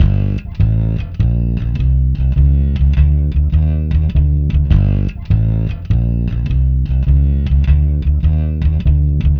Ala Brzl 1 Fnky Bass-G.wav